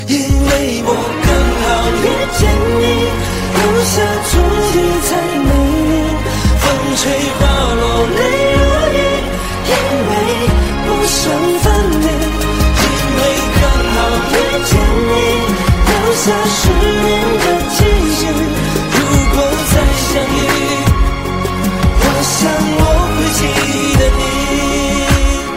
Nhạc Chuông Nhạc Hoa